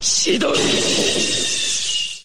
Chidori Sasuke Uchiha Sound Effect Free Download